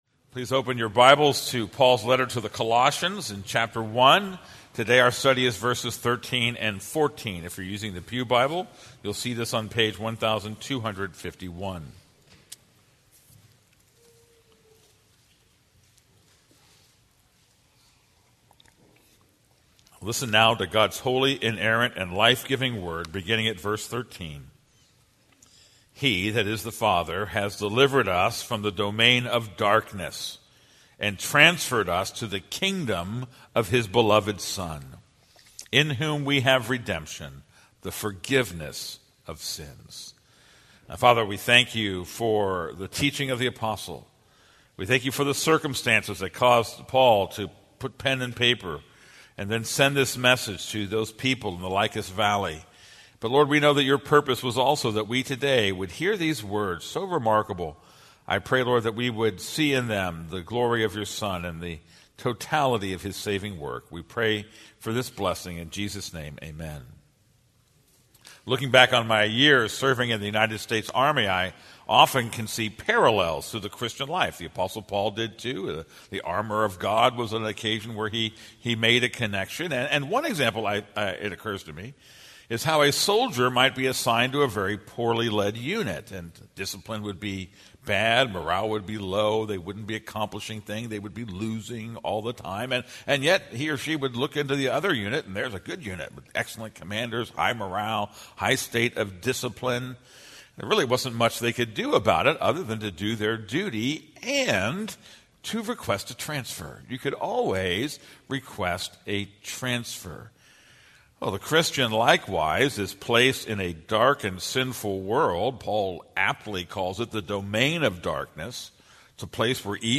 This is a sermon on Colossians 1:13-14.